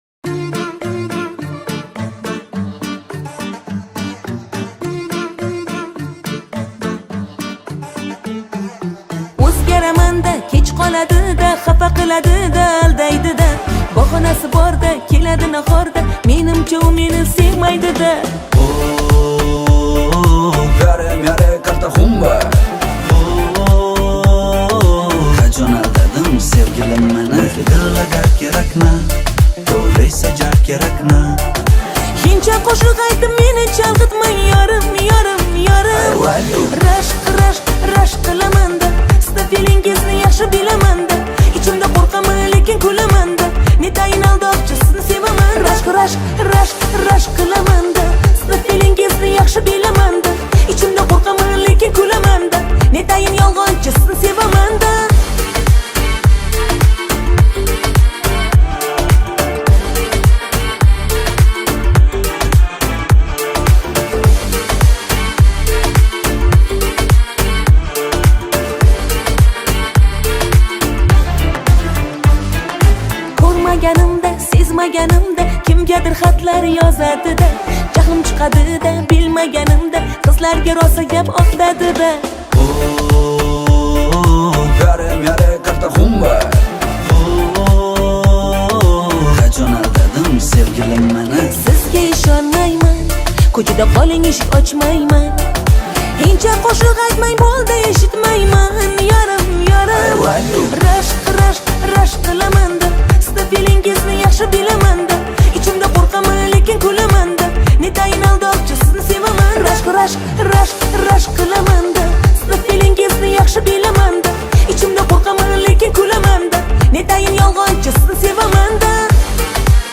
Восточная песня